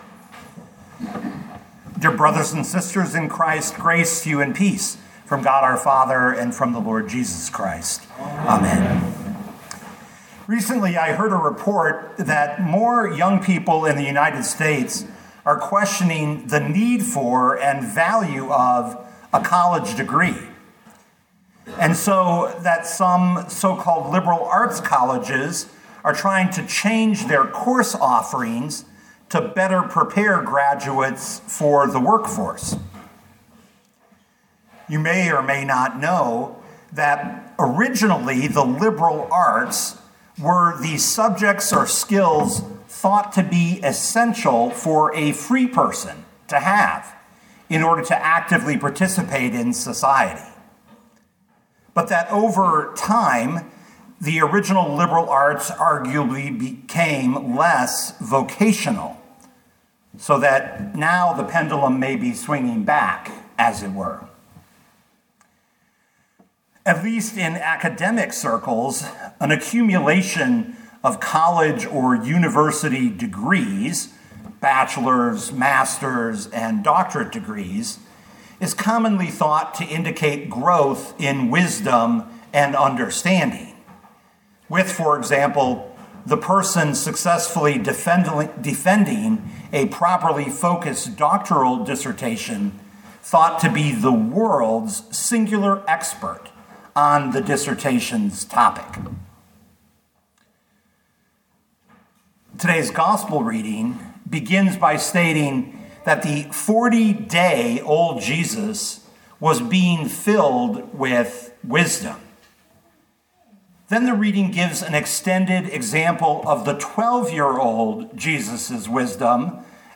2026 Luke 2:40-52 Listen to the sermon with the player below, or, download the audio.